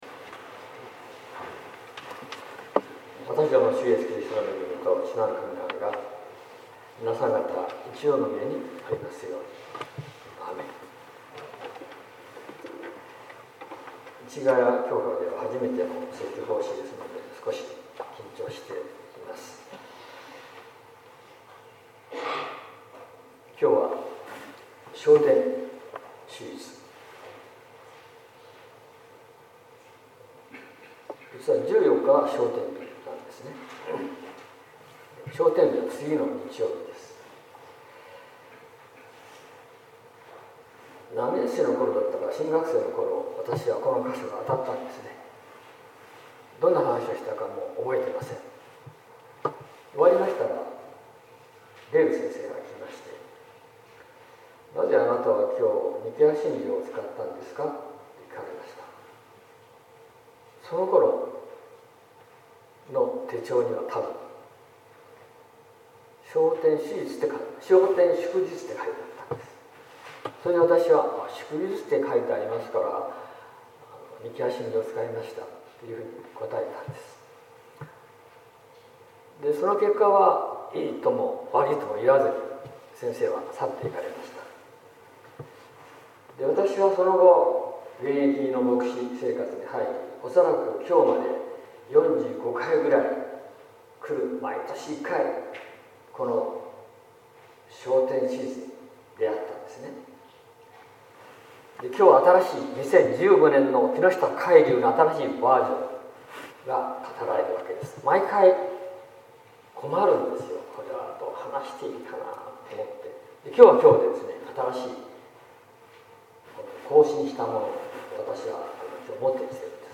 説教「異次元を目撃する者」（音声版）